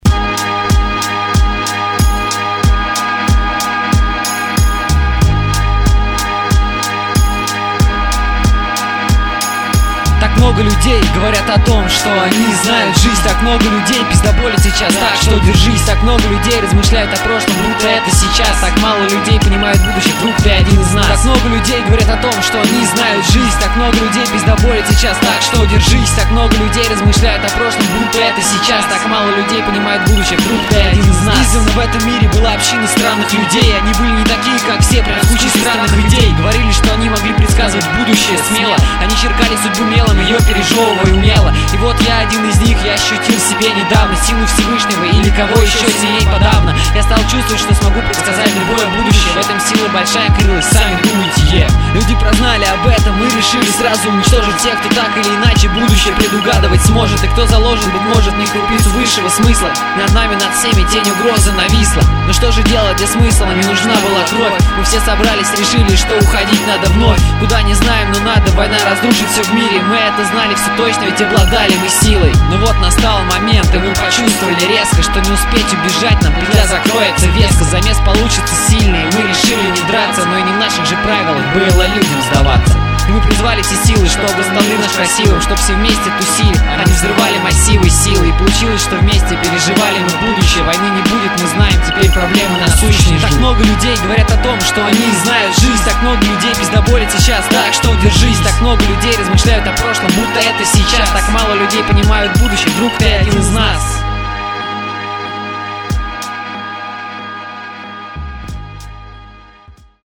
• Жанр: Рэп
перед записью голос сорвал, пока гроулил..вернее пытался..)